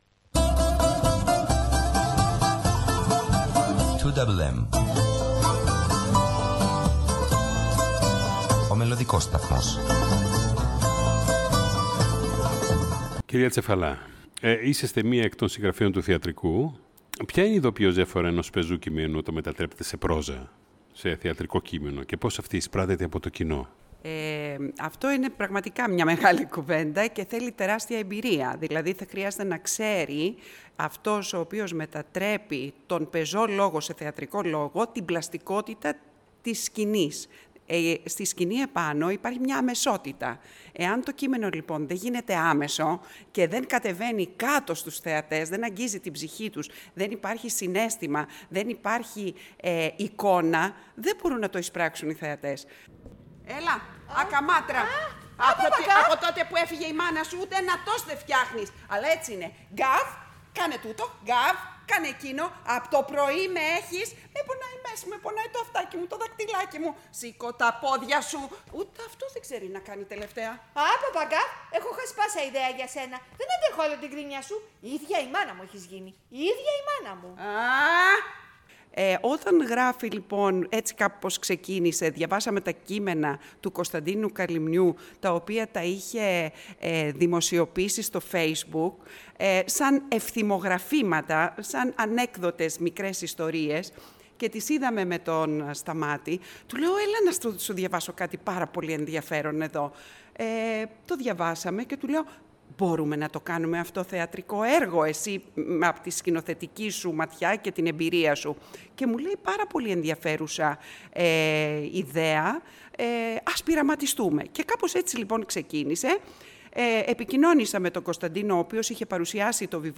σε συνέντευξη